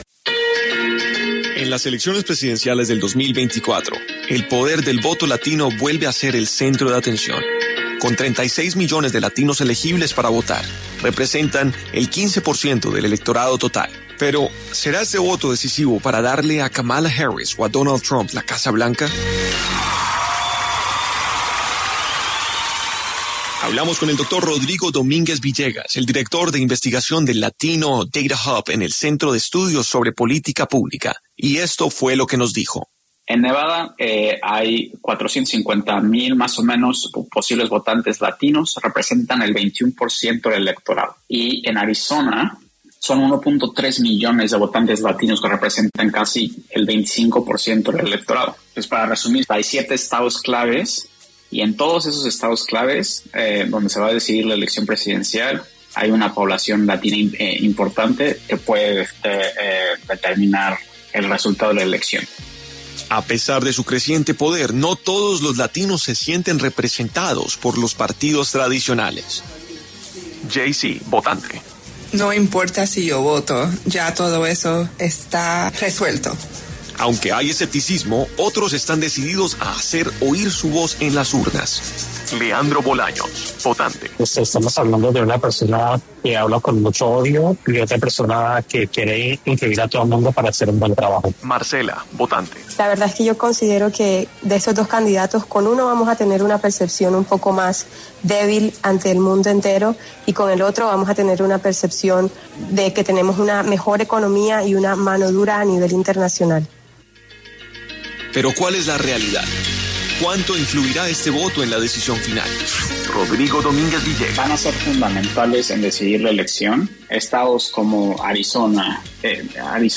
Una crónica que analiza cómo este grupo se convierte en un factor decisivo y qué influye en sus decisiones de voto.